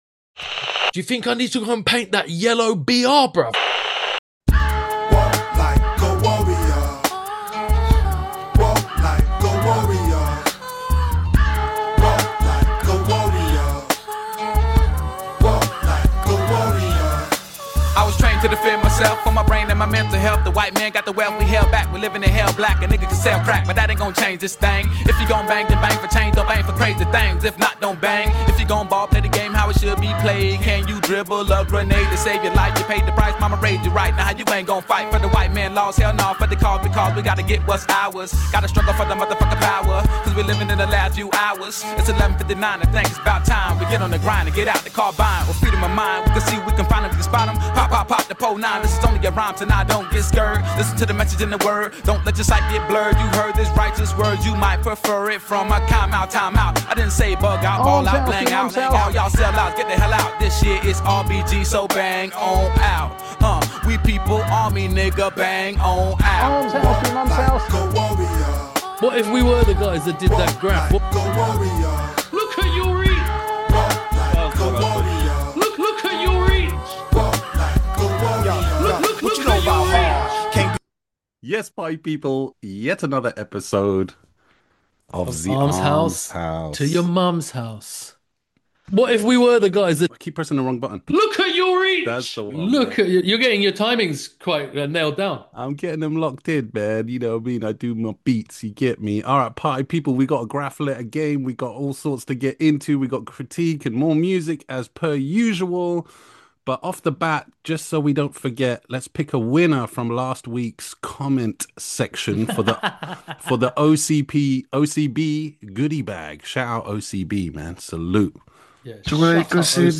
Music and Graff Crits as per usual...